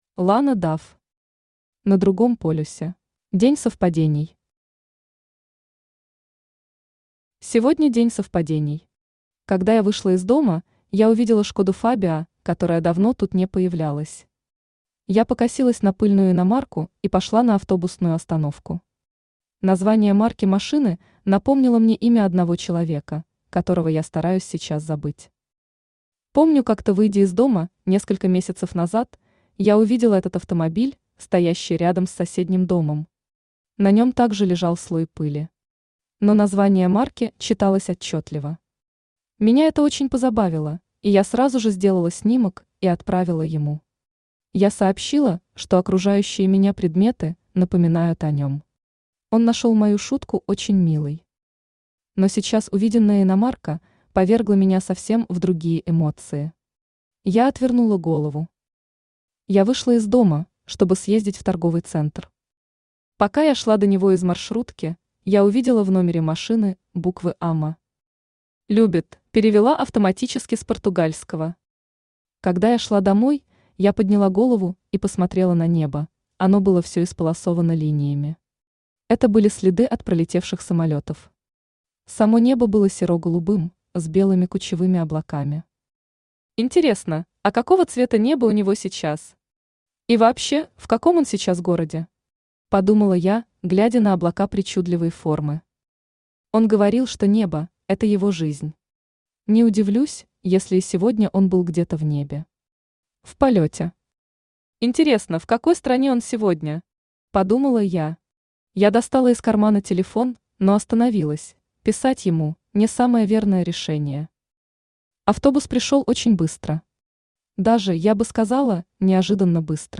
Aудиокнига На другом полюсе Автор Lana Dove Читает аудиокнигу Авточтец ЛитРес.